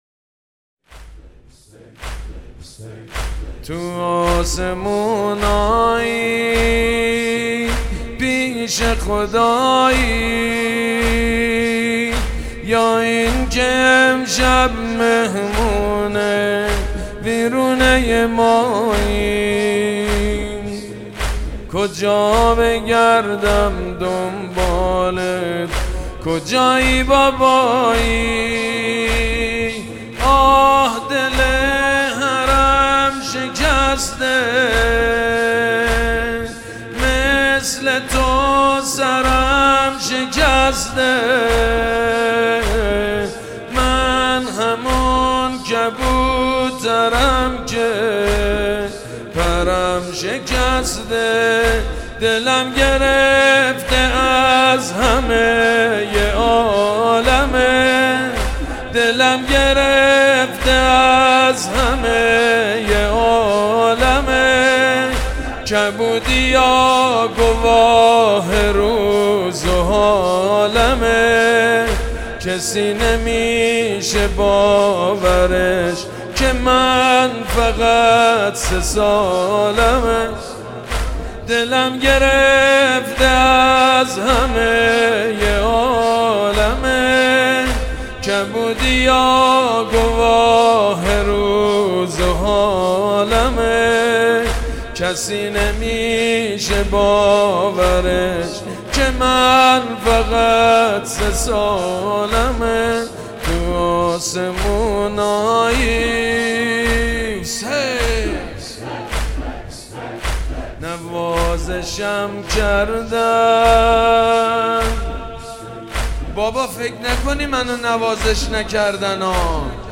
تو آسمونایی مداحی جدید سید مجید بنی فاطمه شب سوم محرم 1400